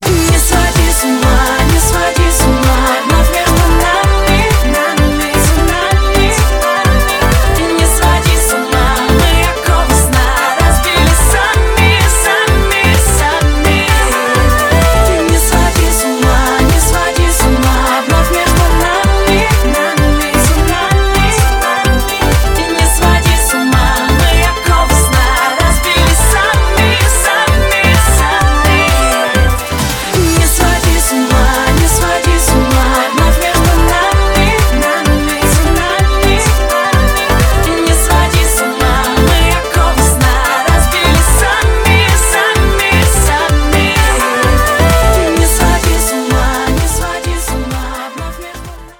попса